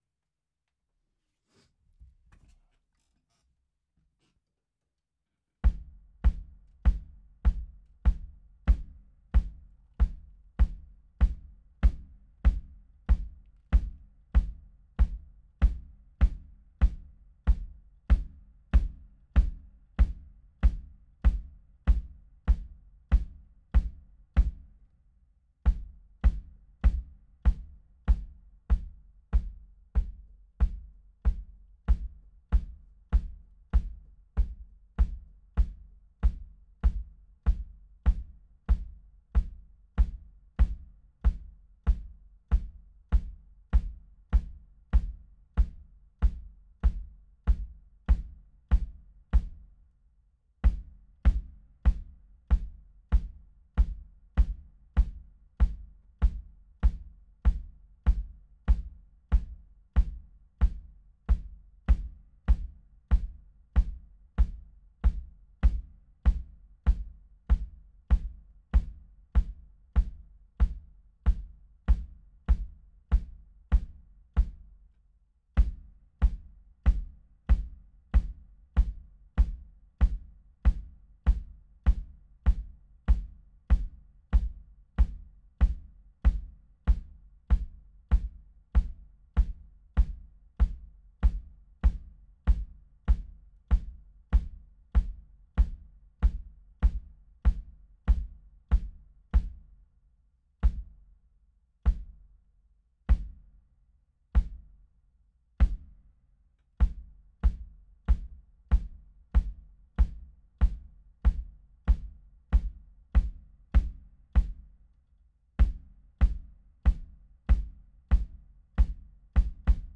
JJM studioBebel, Bielefeld